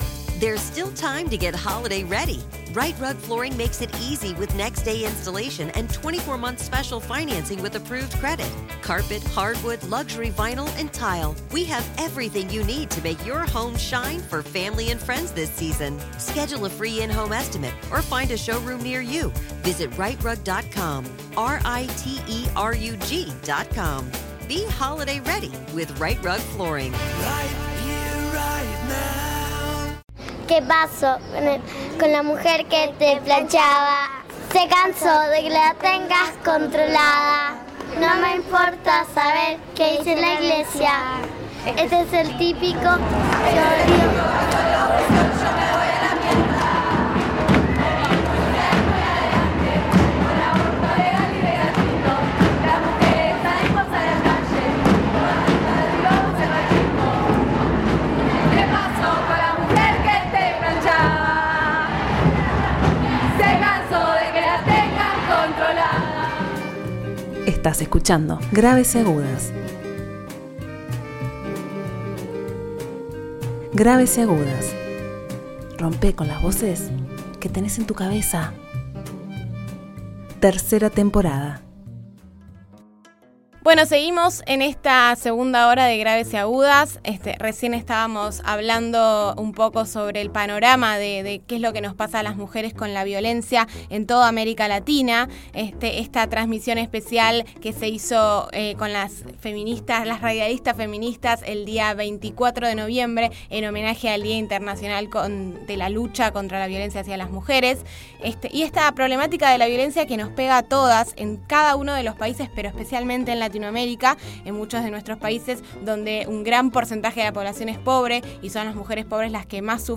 Radialistas feminista de Argentina, Chile, Uruguay organizamos una transmisión colectiva feminista por el 25 de noviembre - Día Internacional contra la Violencia hacia las Mujeres. Compartimos un repaso de la situación de los derechos en las mujeres en la región.
-Testimonios de compañeras campesinas de Mendoza.